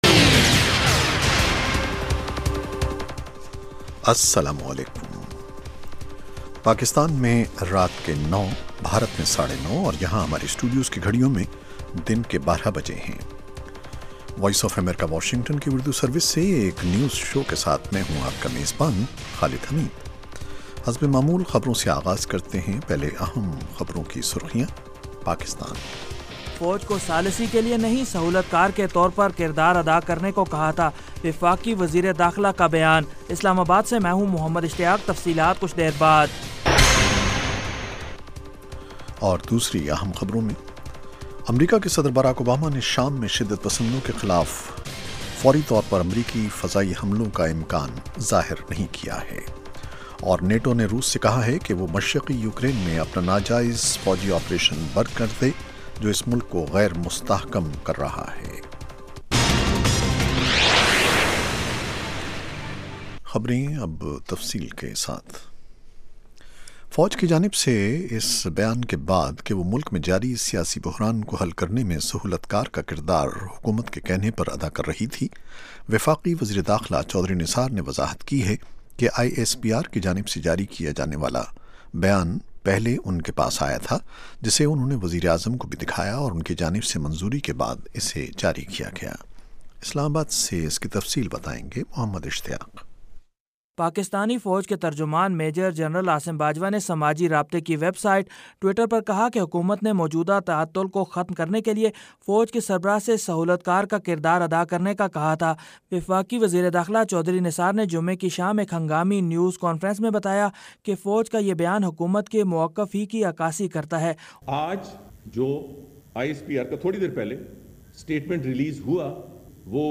9:00PM اردو نیوز شو